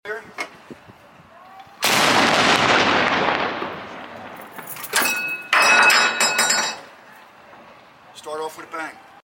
Firing the 40mm saluting gun! sound effects free download